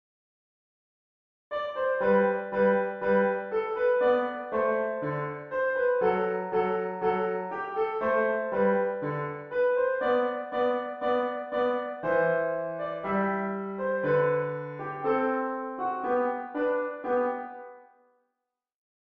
Air for Easy/Level 2 Piano Solo
air-mozart-piano.mp3